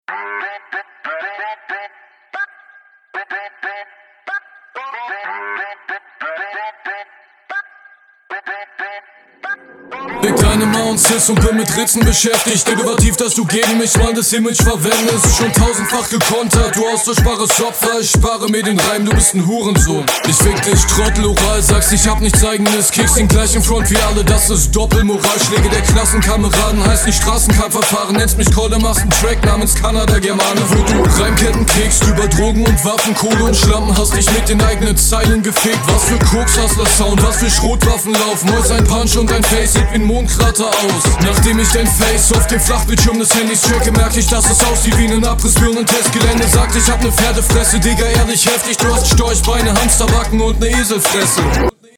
Mische definitiv besser.